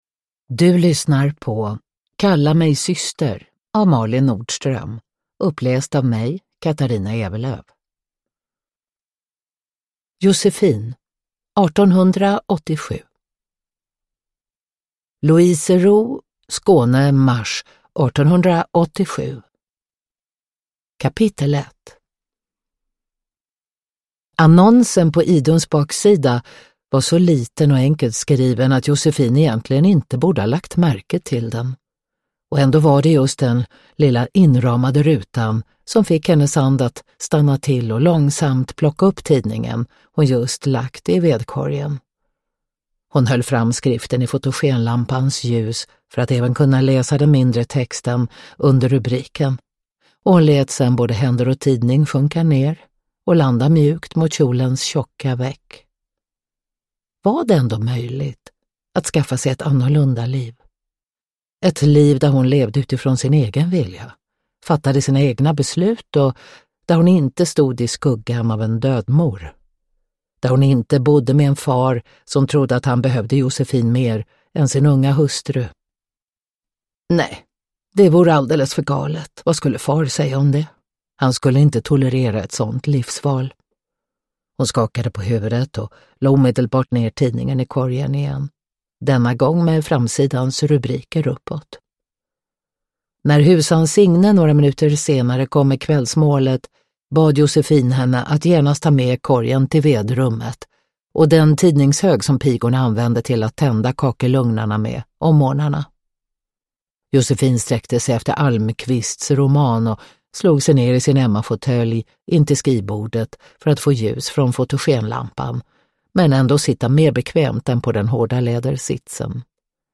Kalla mig syster (ljudbok) av Malin Nordström